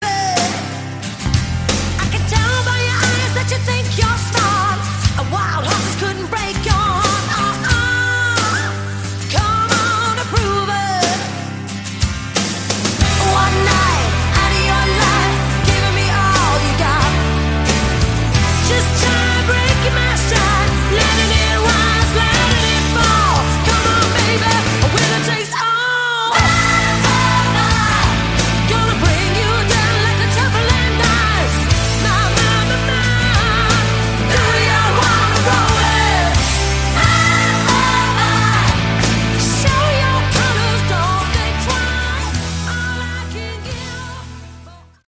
Category: Hard Rock
electric and acoustic guitars, keyboards, mandolin
lead vocals
bass, keyboards
lead and rhythm guitars
drums, percussion